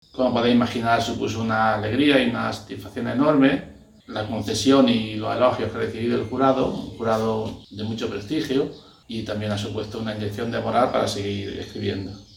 Todos los premiados  agradecen el galardón en una gala difundida en Youtube con intervención de todos los jurados y responsables institucionales